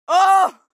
دانلود آهنگ دعوا 27 از افکت صوتی انسان و موجودات زنده
دانلود صدای دعوا 27 از ساعد نیوز با لینک مستقیم و کیفیت بالا
جلوه های صوتی